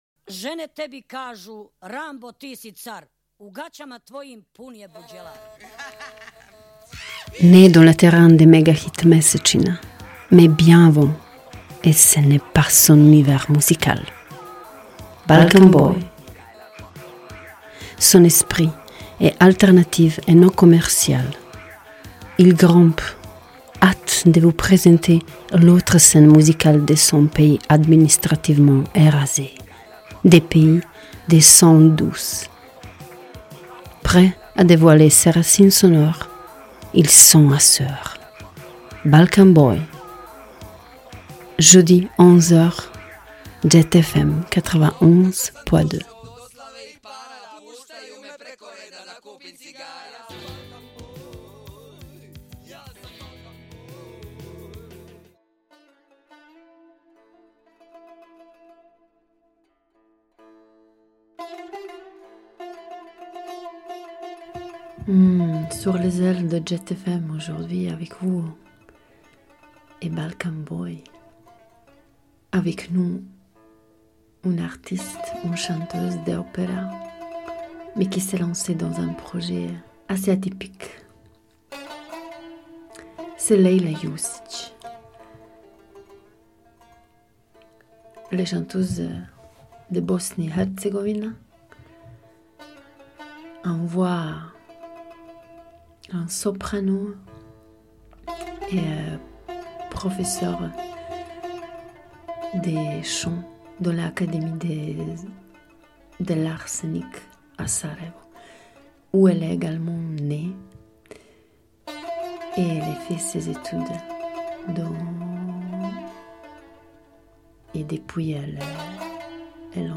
chanteuse d’opéra bosniaque, soprano